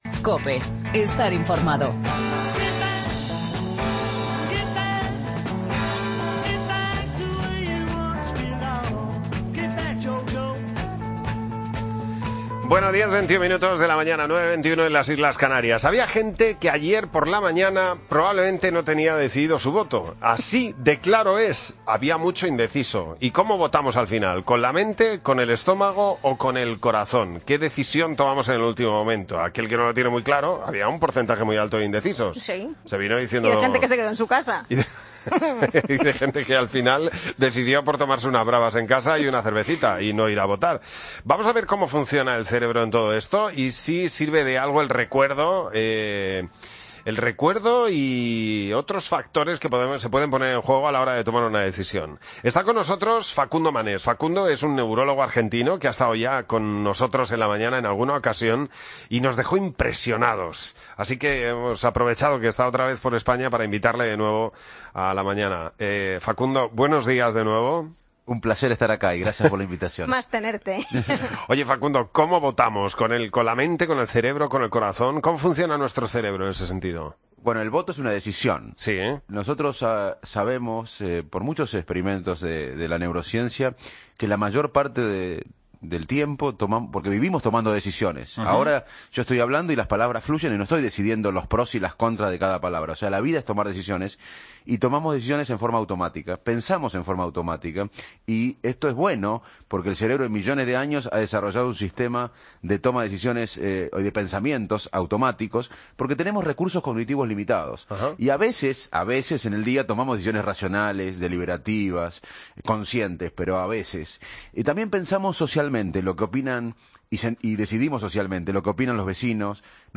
AUDIO: Te lo cuenta el neurólogo Facundo Manes en La Mañana